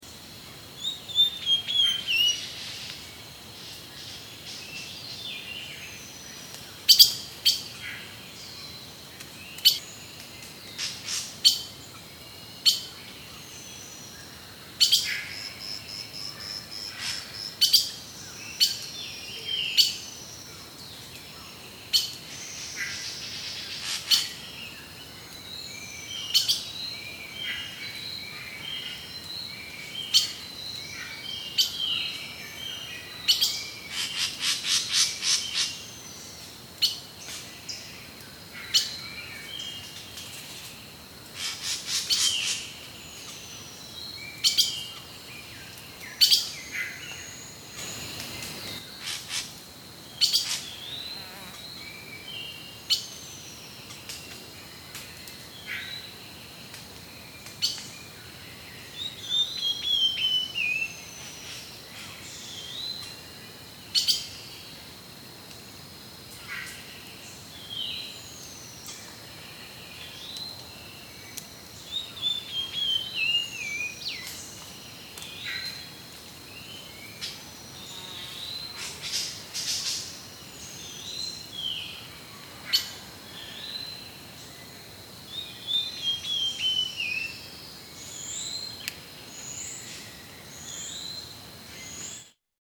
Другие рингтоны по запросу: | Теги: звуки природы